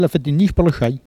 Langue Maraîchin